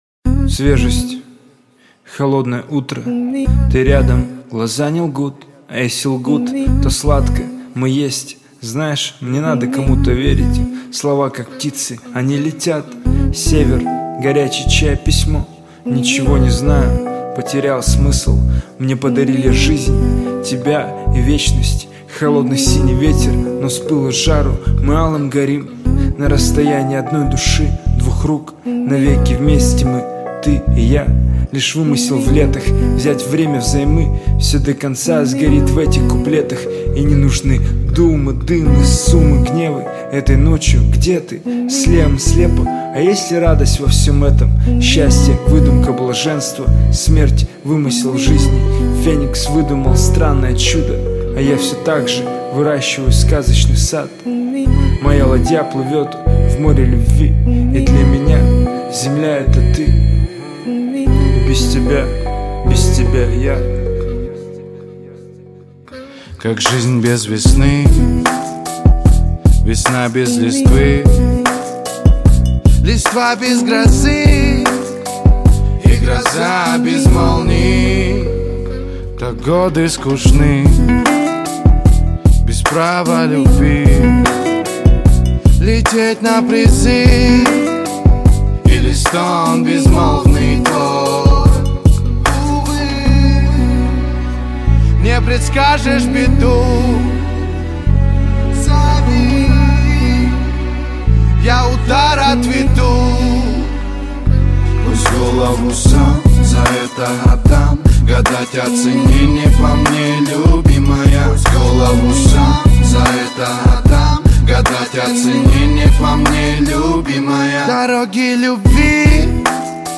Жанр: Жанры / Русский рэп